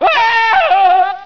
scream2.ogg